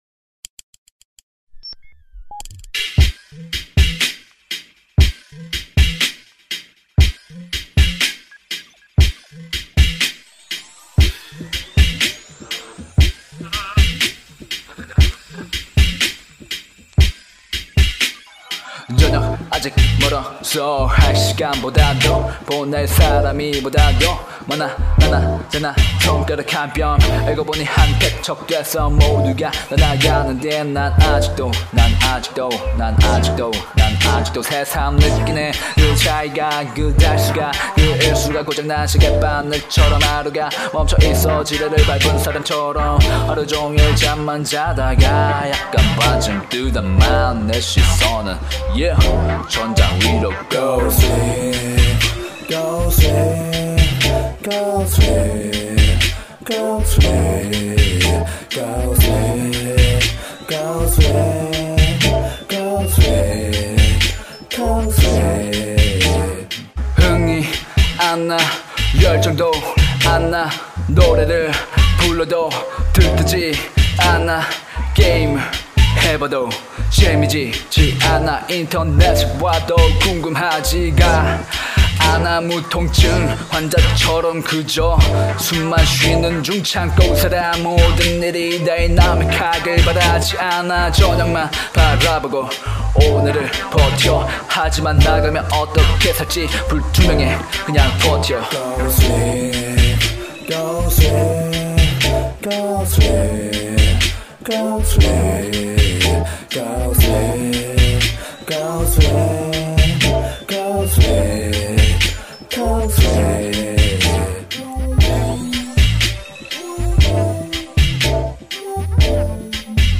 Rap/Vocal by : 나
Mixing by :  나